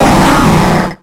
Cri de Foretress dans Pokémon X et Y.